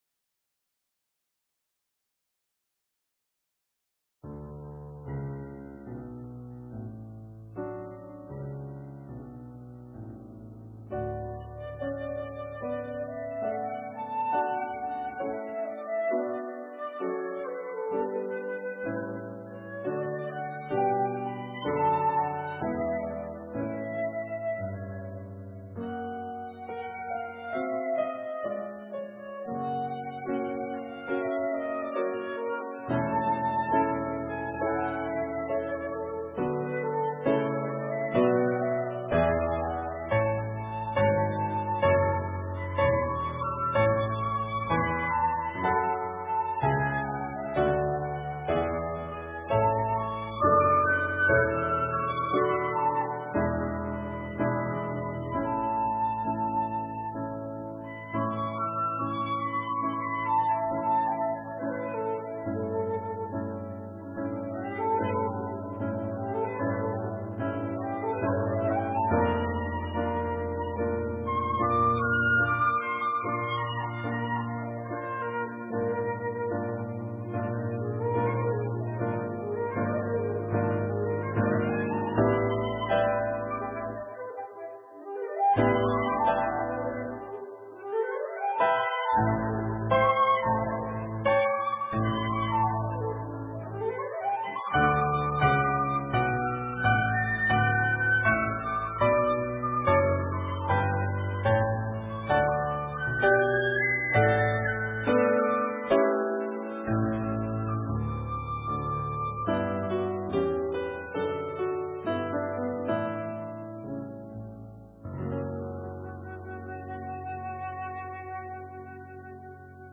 The worship service begins 15 minutes into the recordings